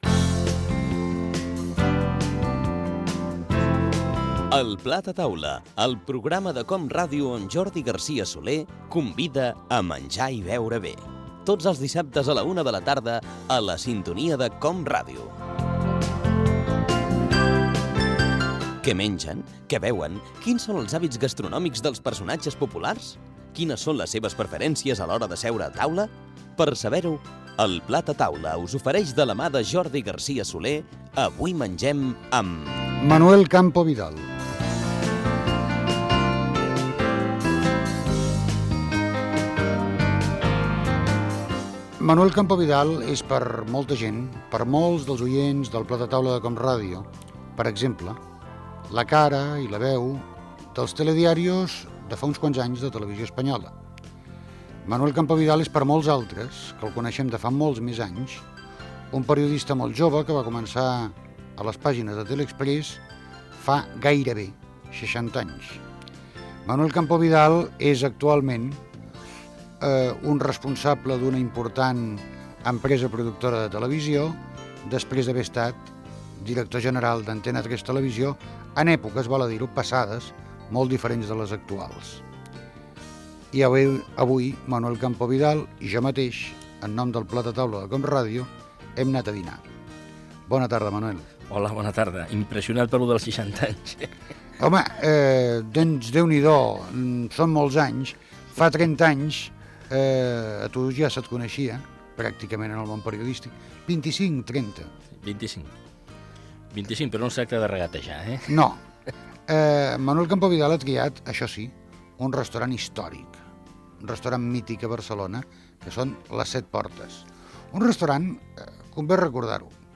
Careta del programa, presentació i fragment d'una entrevista al periodista Manuel Campo Vidal
Divulgació